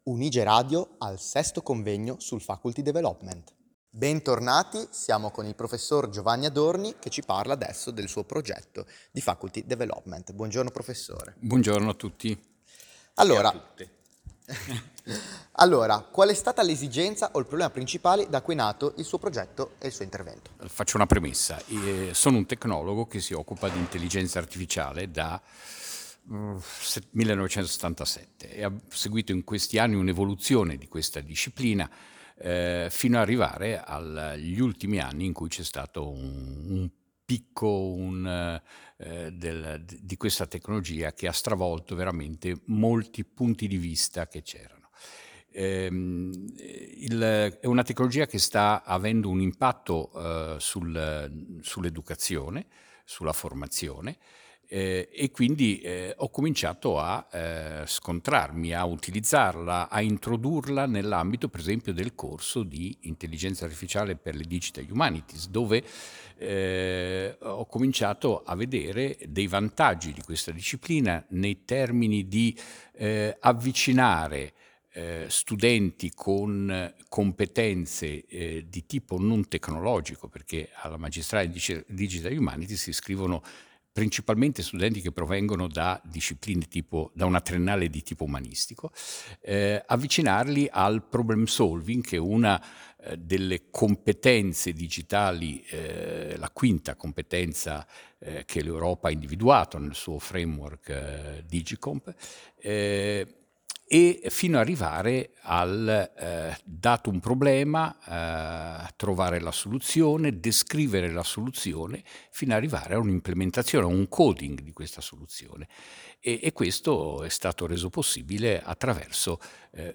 Una lunga intervista